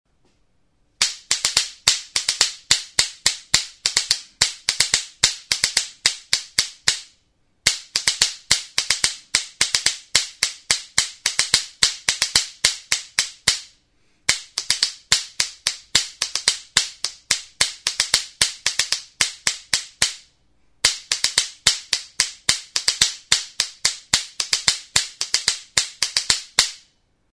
Idiophones -> Struck -> Indirectly
Recorded with this music instrument.
Arto zuztarrarekin egindako kaskabeleta da.